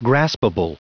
Prononciation du mot graspable en anglais (fichier audio)
Prononciation du mot : graspable